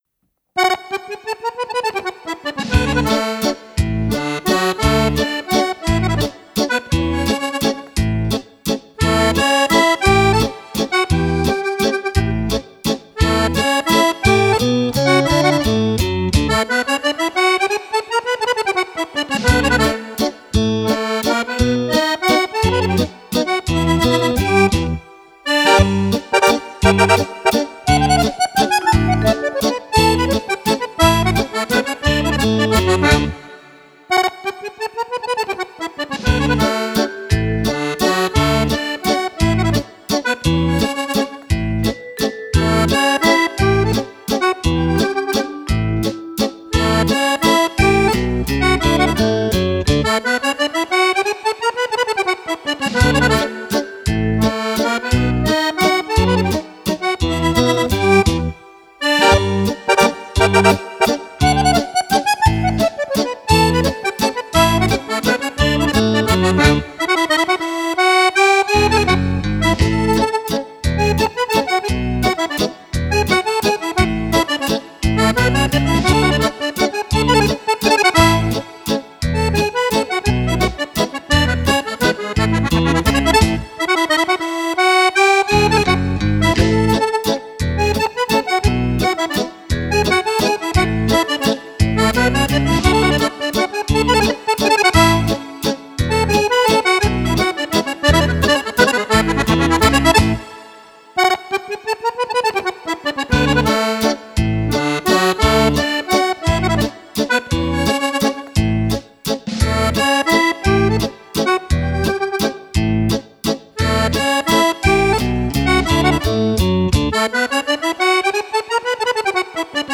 Valzer
primo CD di Fisarmonica solista.